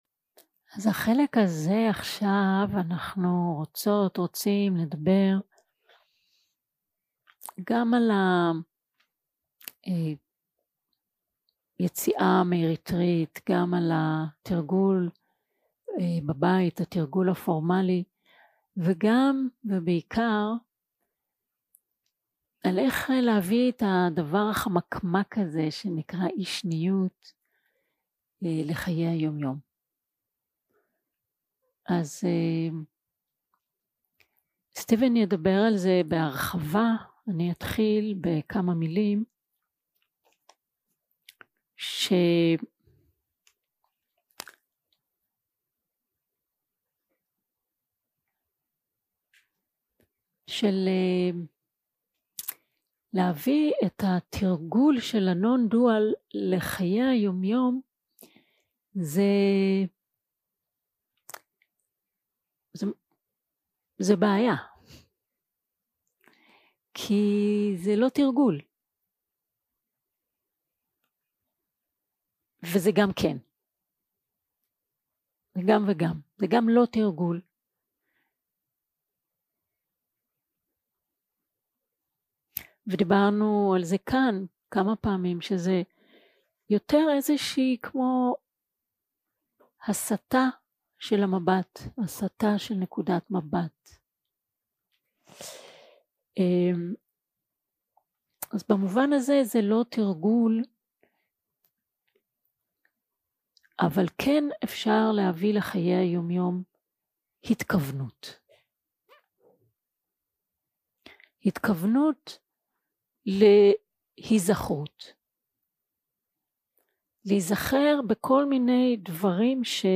יום 4 – הקלטה 8 – צהריים – שיחת סיכום – אי-שניות בחיי היום-יום 0:00 0:00 סוג ההקלטה: Dharma type: Closing talk שפת ההקלטה: Dharma talk language: Hebrew